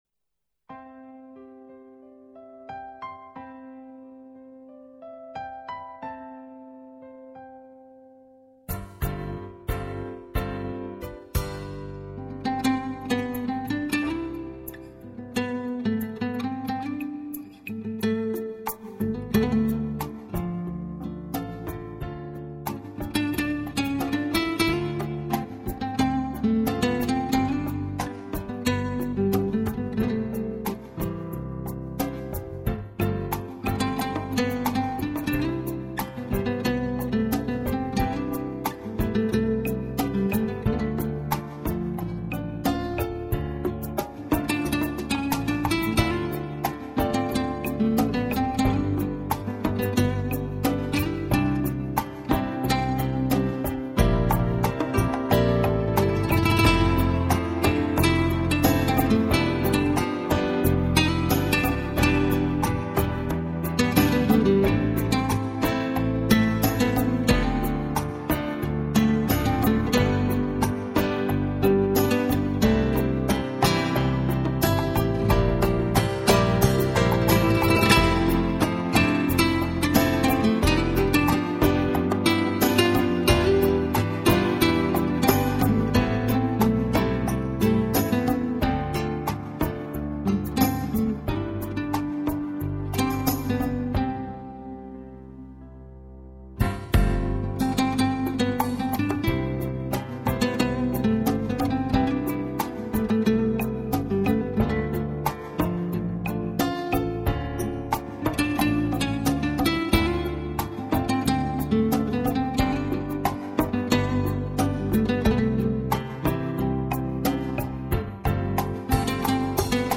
0251-吉他名曲为了一个女人爱.mp3